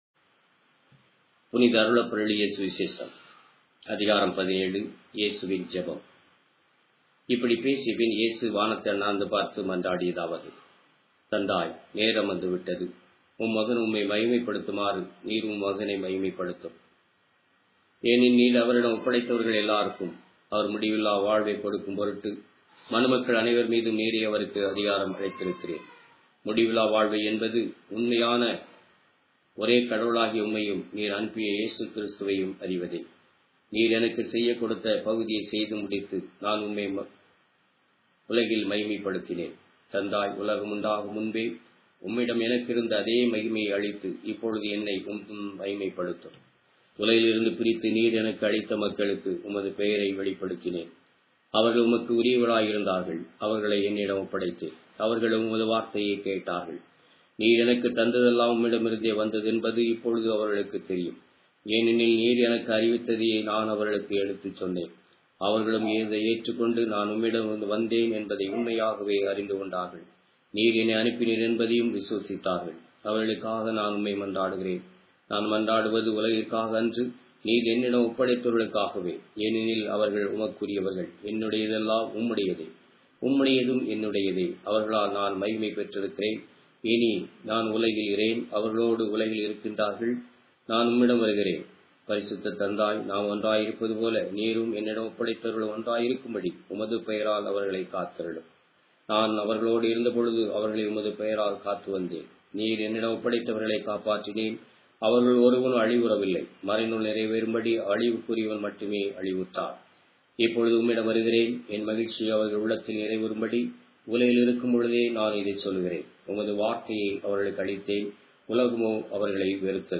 Tamil Audio Bible - John 4 in Rcta bible version